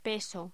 Locución: Peso
voz